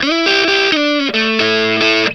BLUESY3 A 90.wav